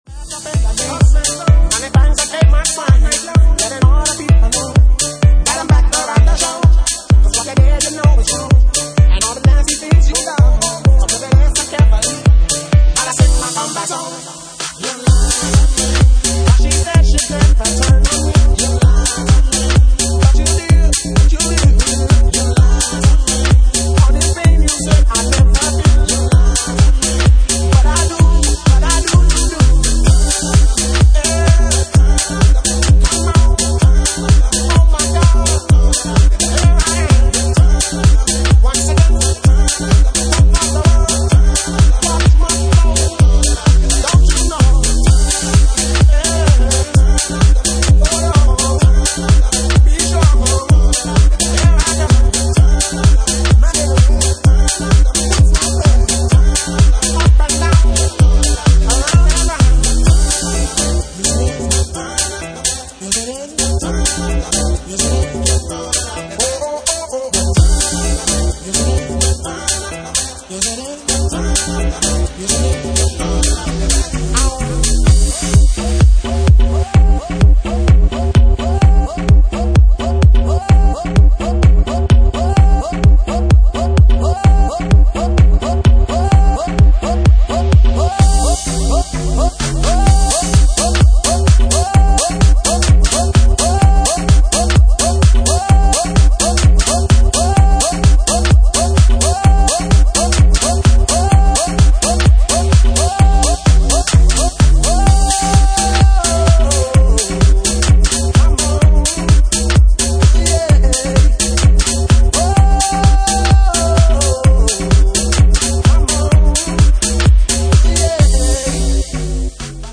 Genre:Jacking House